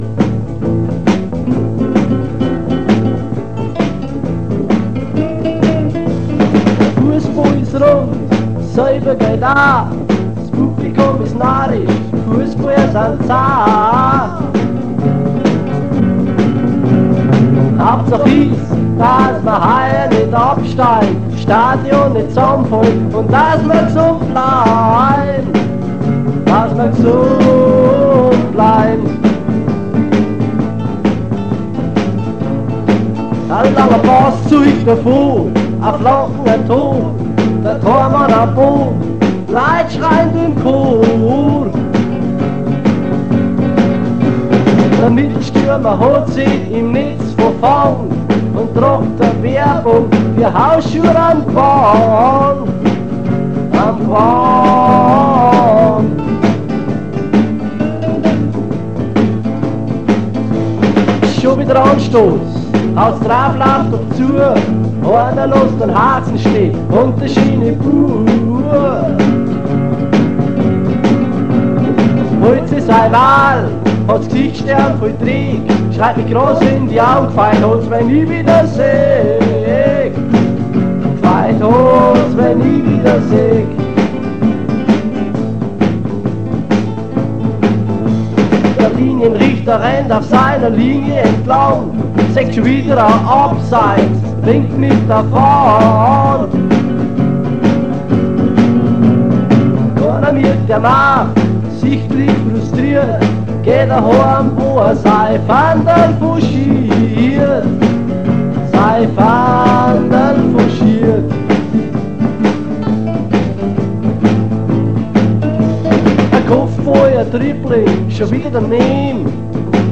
Vozwickte Mundartsongs